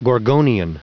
Prononciation du mot gorgonian en anglais (fichier audio)
Prononciation du mot : gorgonian